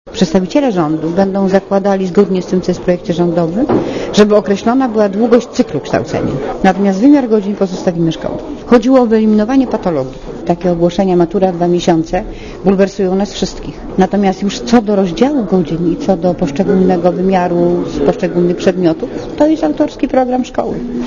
Posłuchaj, co mówi pani minister (84 KB)